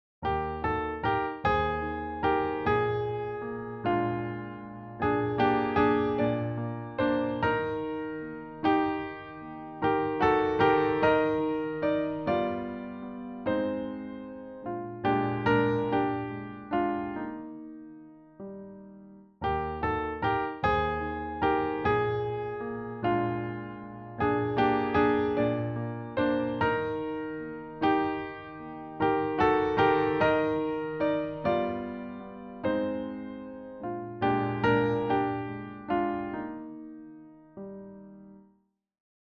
236-piano.mp3